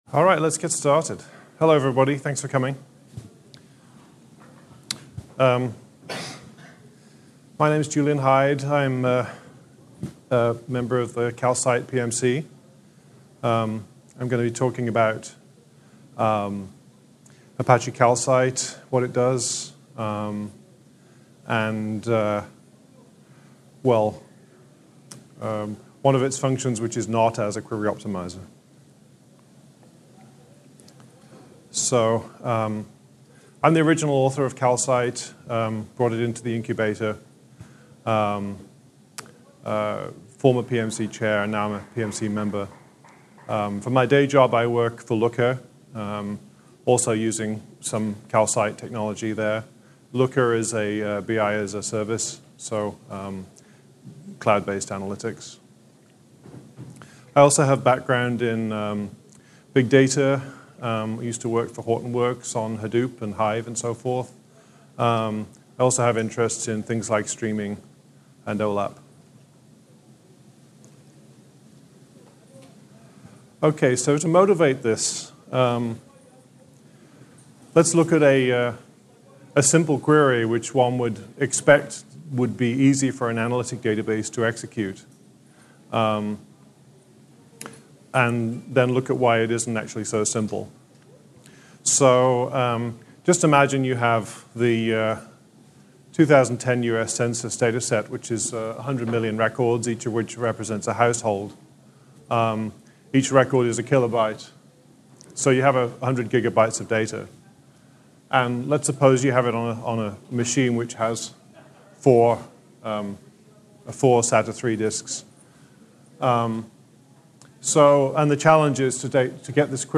Tags: ApacheCon, apacheconNA2018, Podcasts • Permalink